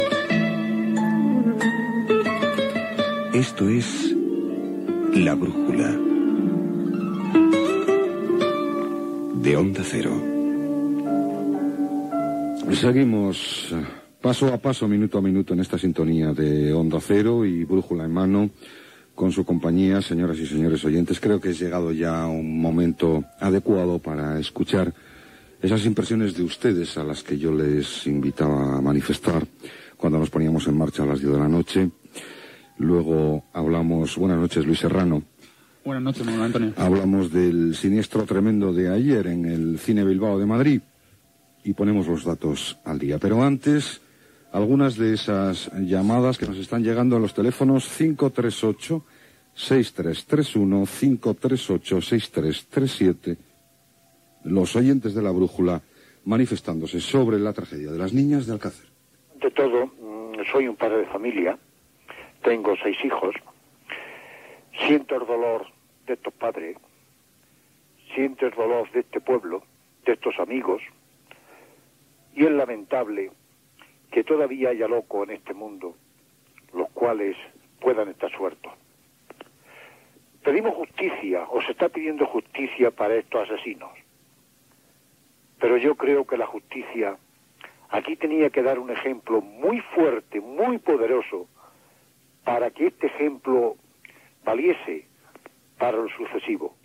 Indicatiu del programa i invitació als oients a opinar sobre la tragèdia de les nenes d'Alcásser.
Informatiu